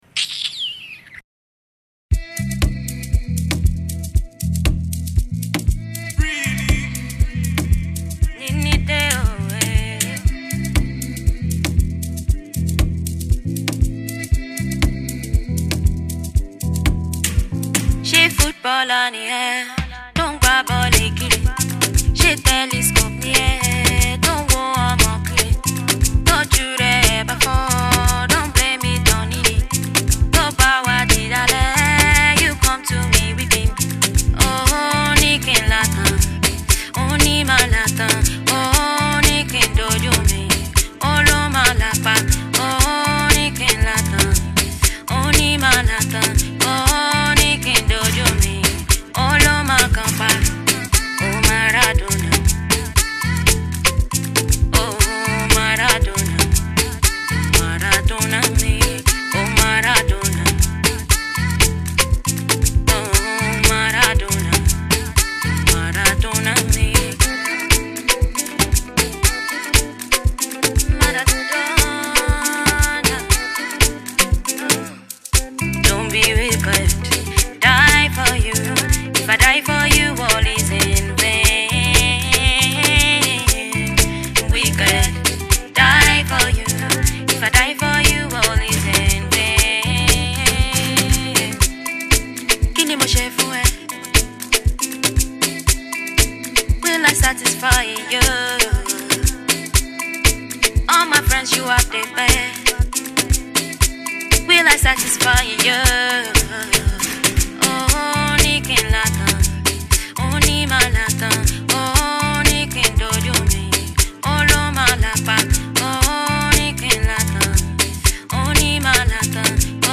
Afro-House